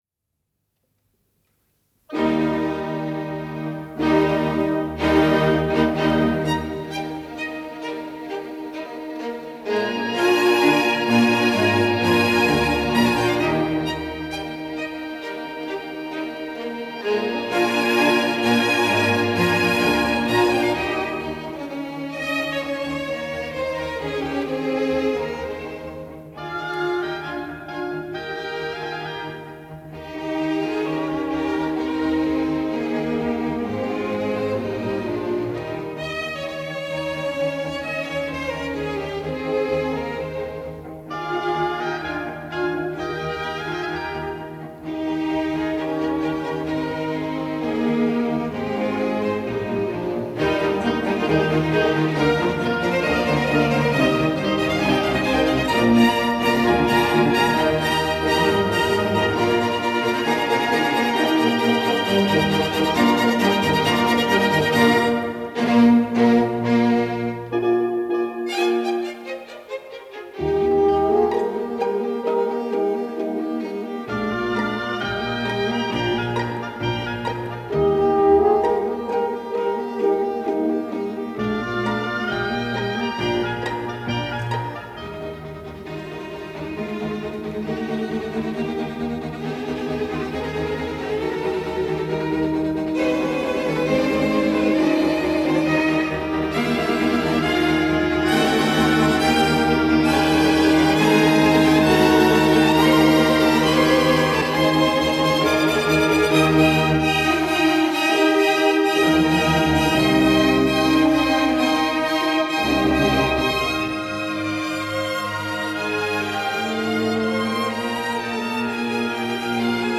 Nothing says Sunday quite like something rare and this weekend it’s the Saar Radio Chamber Orchestra led by Karl Ristenpart and featuring the legendary Lola Bobesco in a performance of Mozart’s Symphonie Concertante K. 364 from this radio broadcast of April 13, 1957 and preserved for posterity by Saar Radio in Germany.